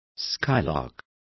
Complete with pronunciation of the translation of skylark.